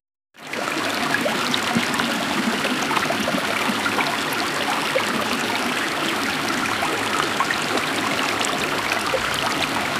A calm and soothing river soundscape with gentle flowing water, and a natural ambience.
a-calm-and-soothing-river-nfwlvftc.wav